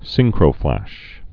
(sĭngkrō-flăsh, sĭn-)